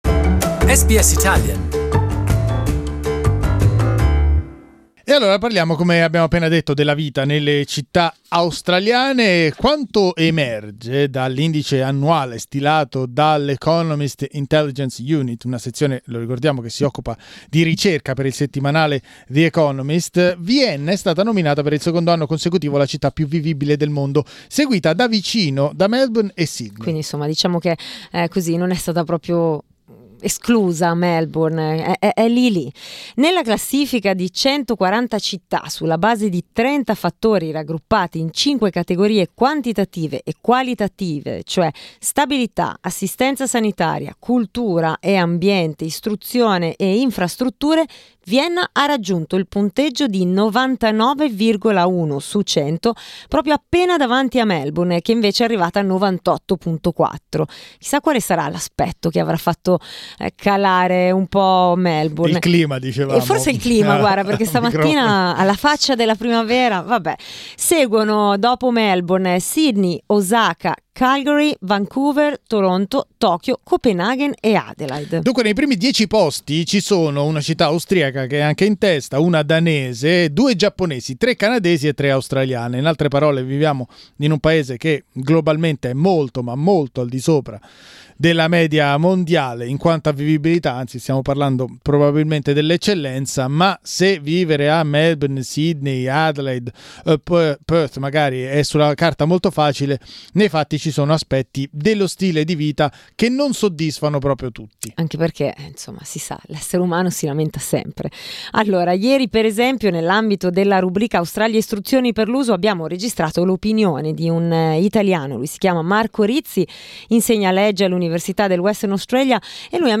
We have asked our listeners to comment the news, click the audio player on the image above to listen to their opinions.